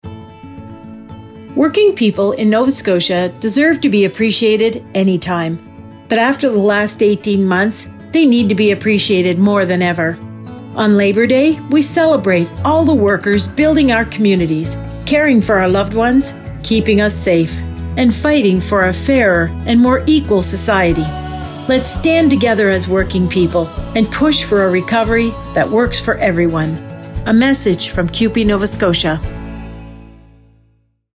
Listen to our Labour Day radio message, playing on stations across the province until September 6.